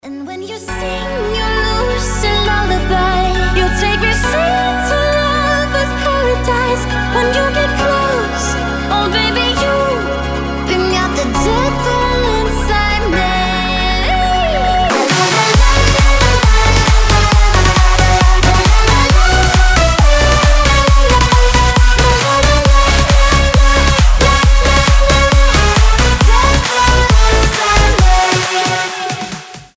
• Качество: 320, Stereo
женский вокал
зажигательные
Electronic
EDM
Big Room